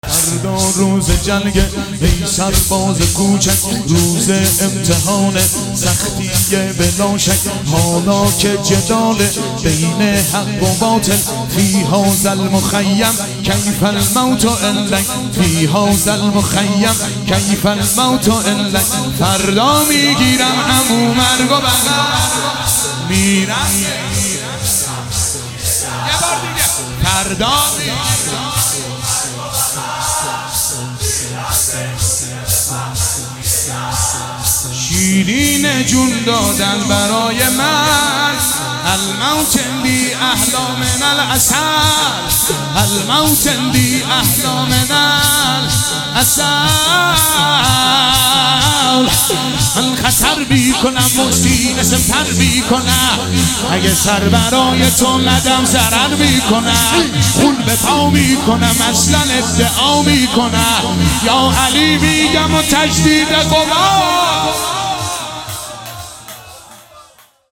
شهادت حضرت معصومه (س) 25 آبان 1400